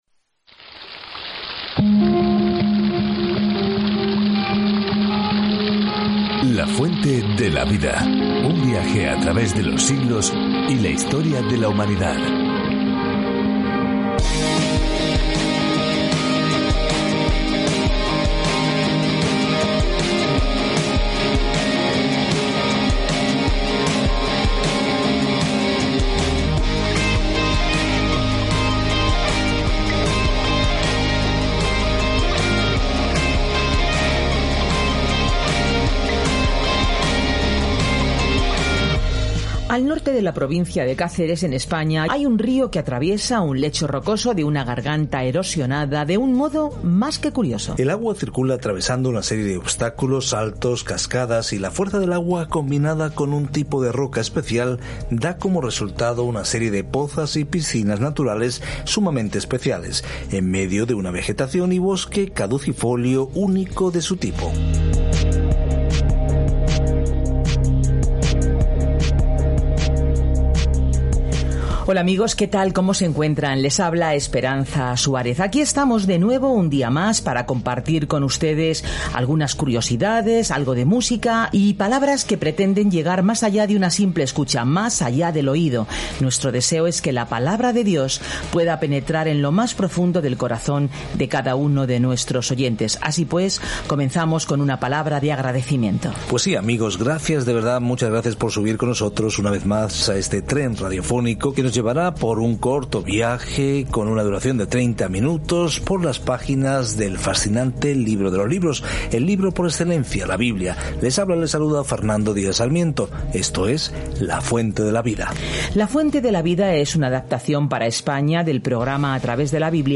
Escritura 1 REYES 19:5-21 1 REYES 20:1-43 Día 13 Iniciar plan Día 15 Acerca de este Plan El libro de Reyes continúa la historia de cómo el reino de Israel floreció bajo David y Salomón, pero finalmente se dispersó. Viaje diariamente a través de 1 Reyes mientras escucha el estudio de audio y lee versículos seleccionados de la palabra de Dios.